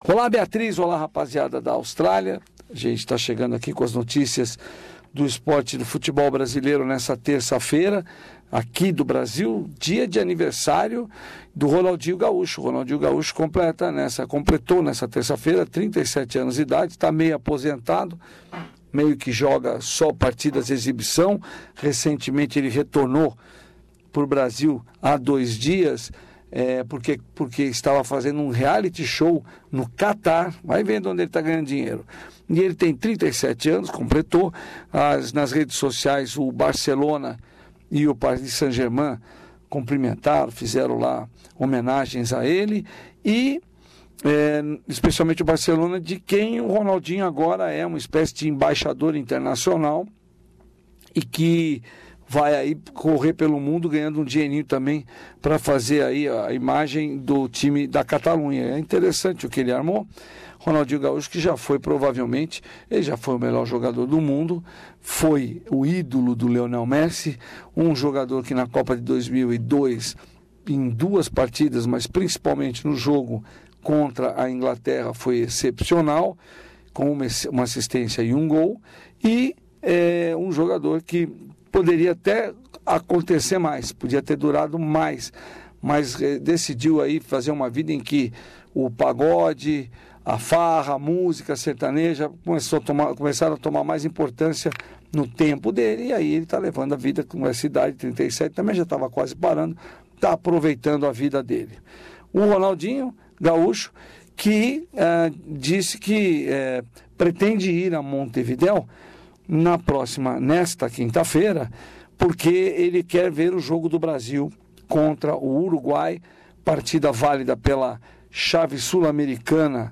Boletim esportivo semanal